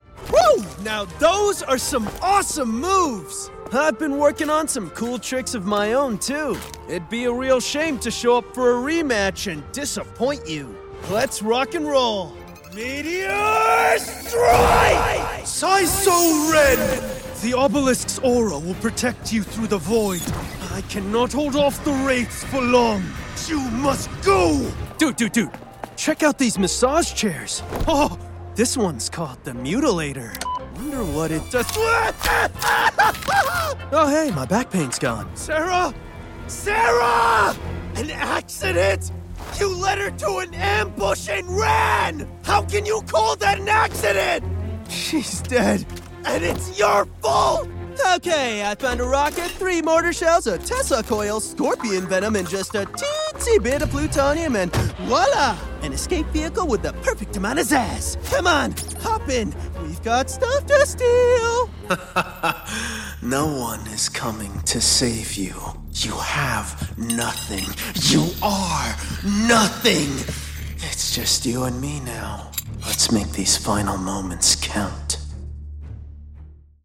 Videogames
Microfone: Neumann U87ai
Tratamento acústico: Broadcast Studio Room